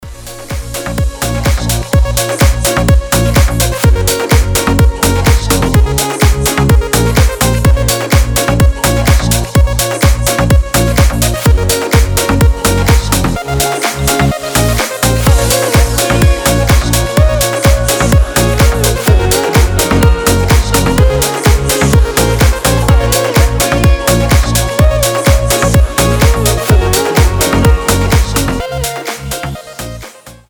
• Качество: 320, Stereo
мелодичные
retromix
без слов
progressive house
ремиксы
Освежающий летний мотив